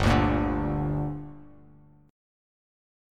Gm Chord
Listen to Gm strummed